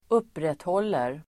Uttal: [²'up:rethål:er]